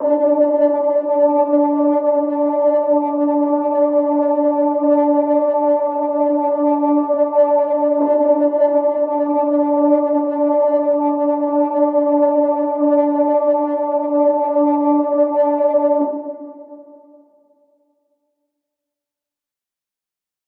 AV_Occult_Pad A#
AV_Occult_Pad-A.wav